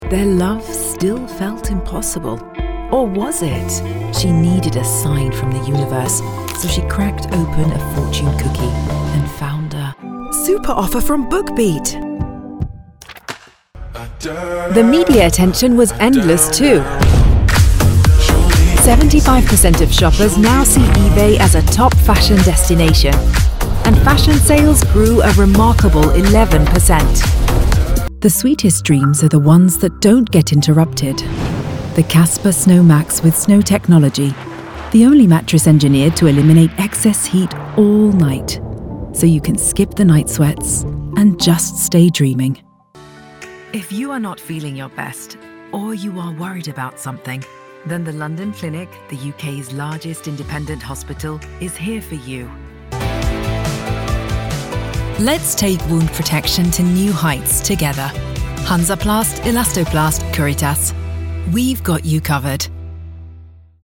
Engels (Brits)
Diep, Natuurlijk, Opvallend, Warm, Zacht
Commercieel